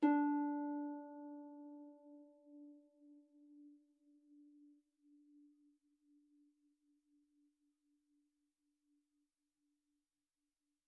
KSHarp_D4_mf.wav